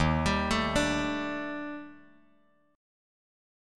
D#M#11 chord